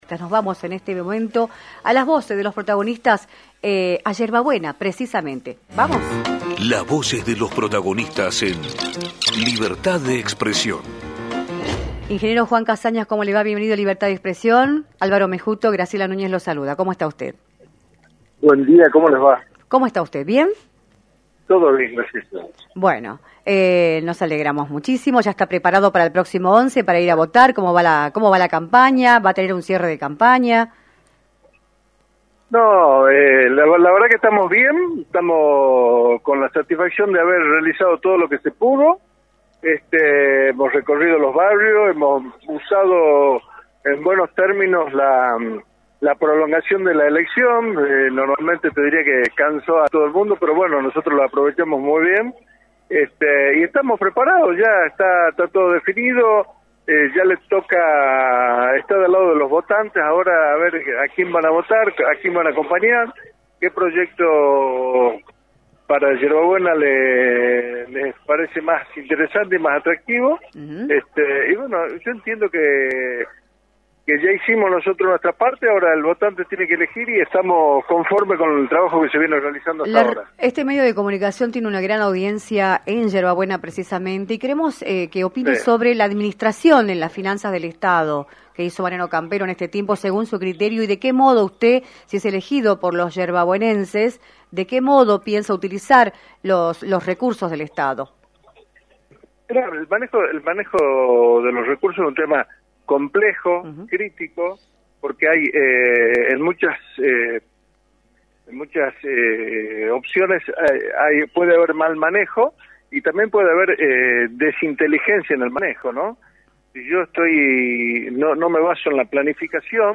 Juan Casañas, ex Diputado Nacional y candidato a Intendente de la ciudad de Yerba Buena, analizó en “Libertad de Expresión” por la 106.9, el escenario electoral de la provincia y señaló sus propuestas, a 4 días de las elecciones establecidas para el 11 de junio.